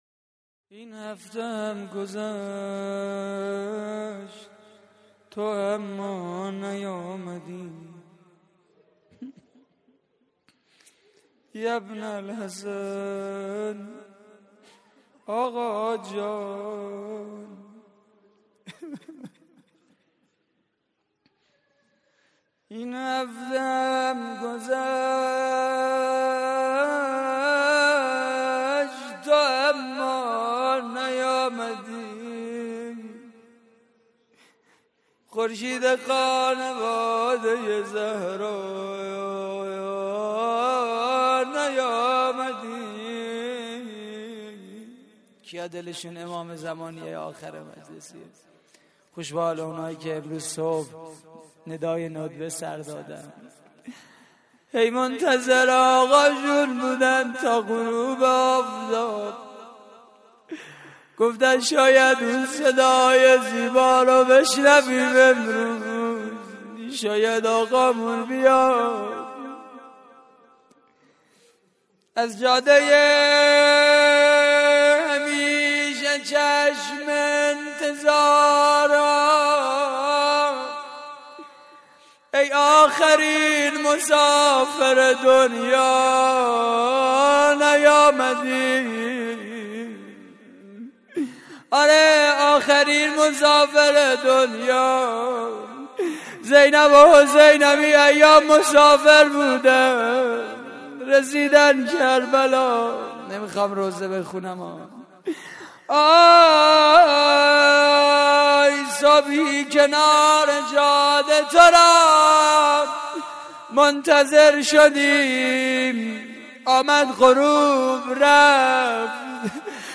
مراسم عزاداری شب دوم ماه محرم / هیئت الزهرا (س) – دانشگاه صنعتی شریف؛ 26 آبان 1391
صوت مراسم:
روضه پایانی: این هفته هم گذشت؛ پخش آنلاین |